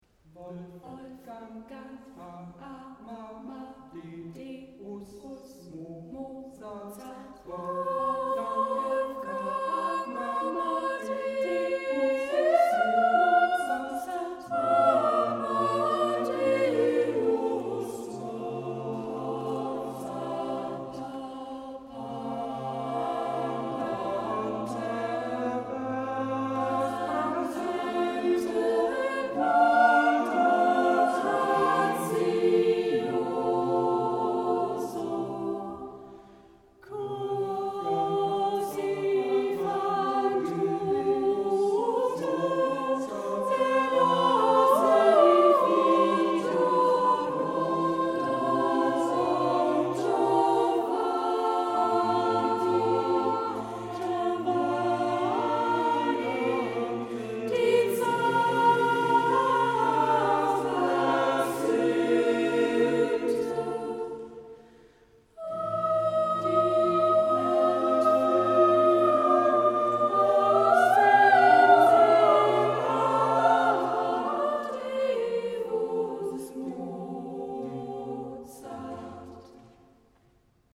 der älteste Jugendchor Berlins. Bei uns singen im Moment 15 Jugendliche und junge Erwachsene im Alter von ca. 16 bis ü30.